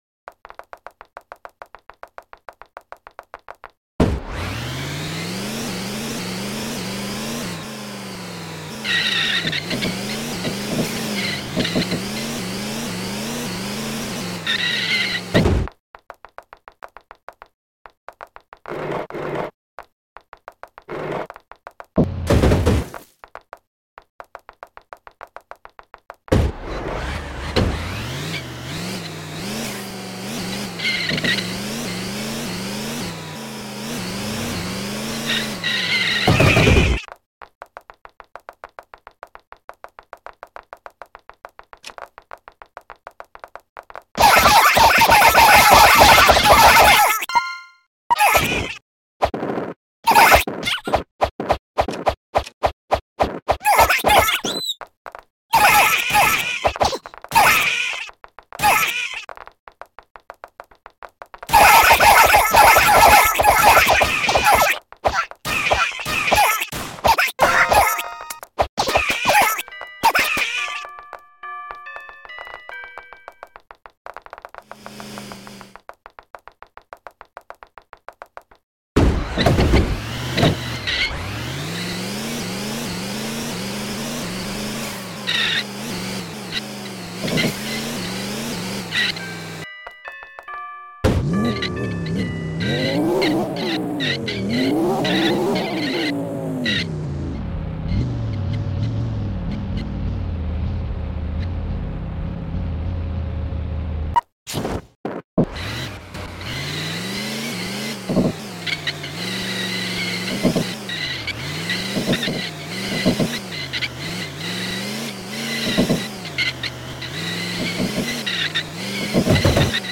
All Police Cars Collection At Sound Effects Free Download